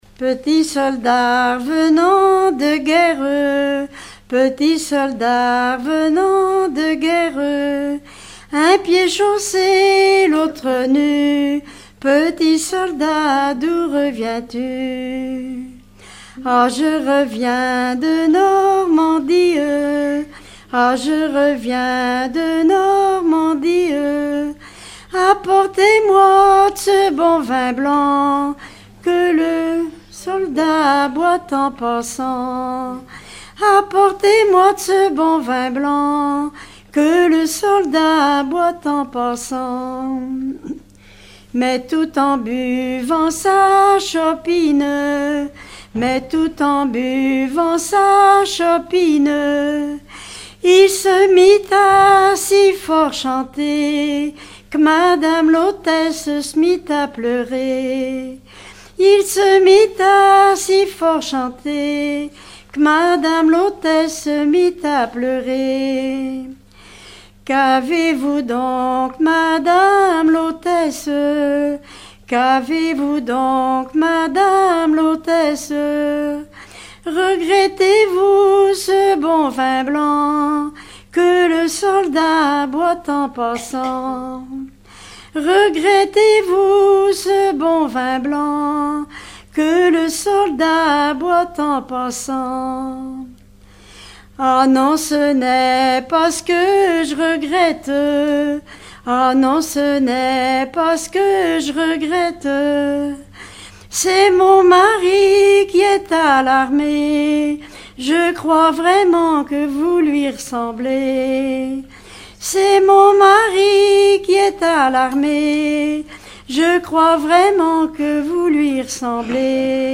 Genre laisse
Regroupement de chanteurs du canton
Pièce musicale inédite